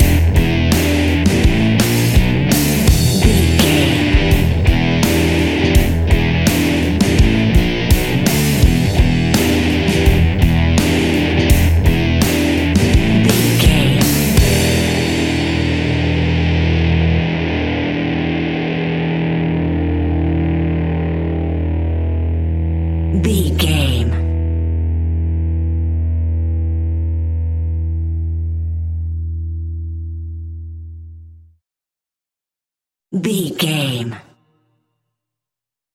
royalty free music
Fast paced
Aeolian/Minor
hard rock
blues rock
distortion
rock instrumentals
Rock Bass
heavy drums
distorted guitars
hammond organ